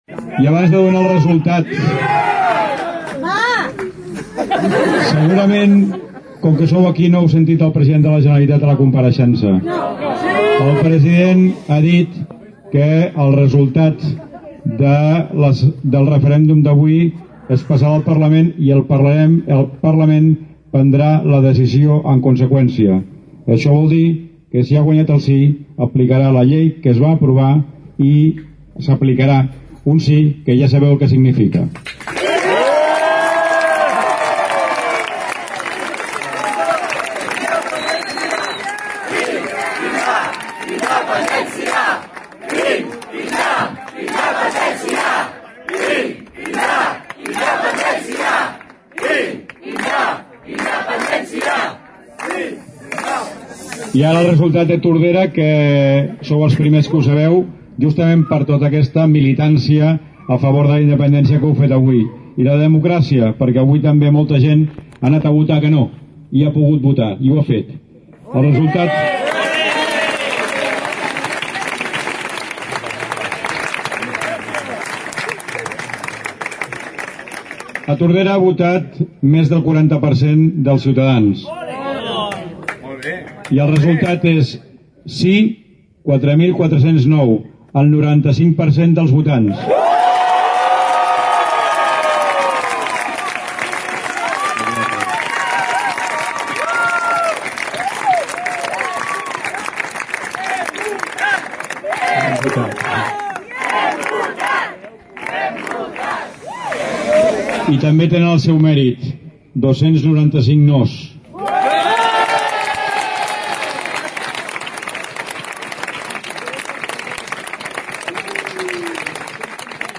Uns resultats que es van demorar més de 3 hores, fins que sobre un quart de 12 de la matinada, l’alcalde de Tordera, Joan Carles Garcia va sortir davant la multitud que l’esperava i va anunciar el resultat final.